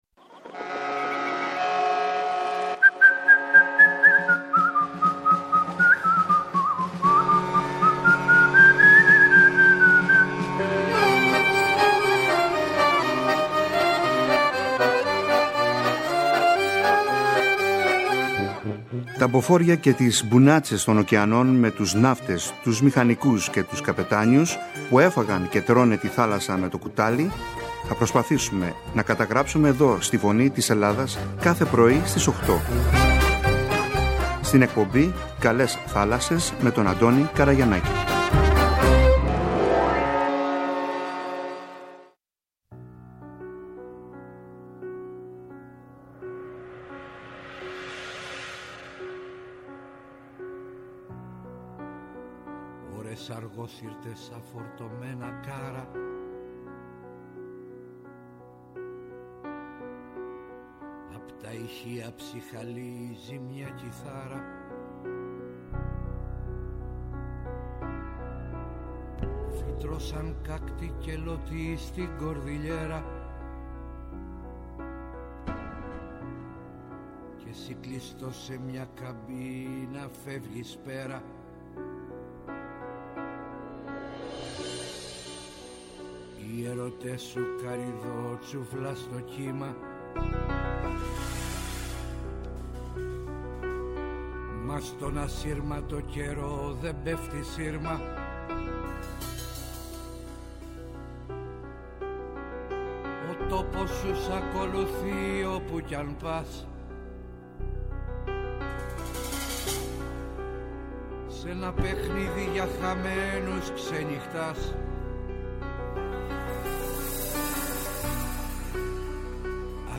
Οι «ΚΑΛΕΣ ΘΑΛΑΣΣΕΣ» και η ΦΩΝΗ ΤΗΣ ΕΛΛΑΔΑΣ θέλοντας να τιμήσουν τη μνήμη και το έργο του έκαναν ένα μικρό αφιέρωμα σε αυτόν με τραγούδια του και τις κυριότερες πτυχές της ζωής του.